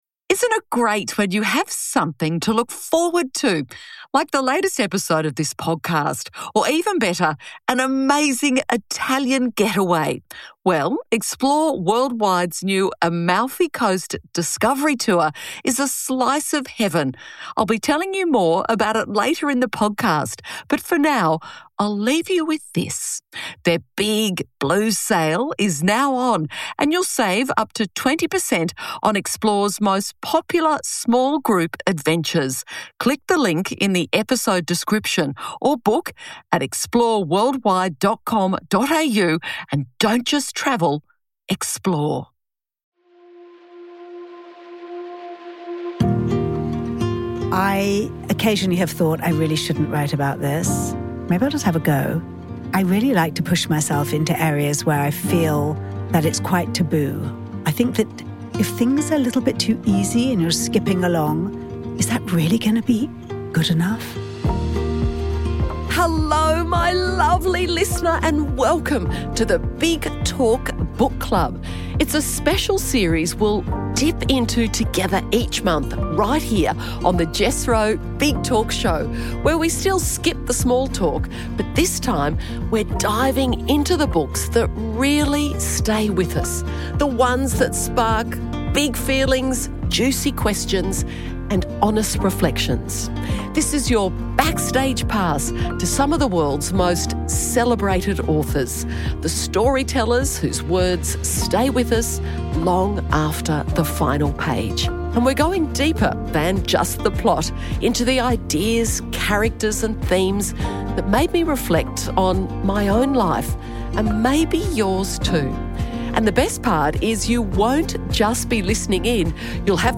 This conversation is with British writer Esther Freud.
Host: Jessica Rowe Guest: Esther Freud